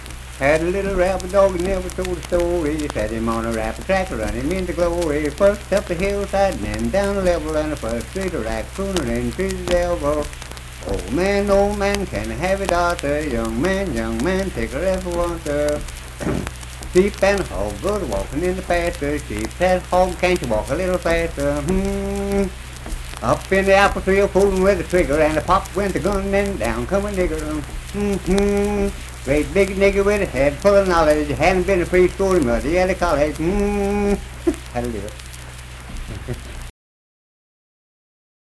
Unaccompanied vocal and banjo music
Verse-refrain 3(4).
Minstrel, Blackface, and African-American Songs
Voice (sung)
Clay County (W. Va.), Clay (W. Va.)